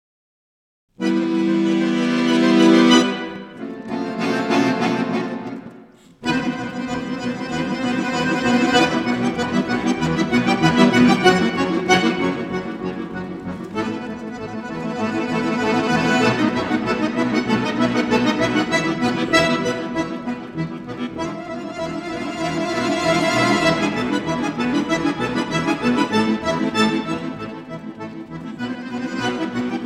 for a Unisono of accordionists
für ein Unisono von Akkordeonisten geschrieben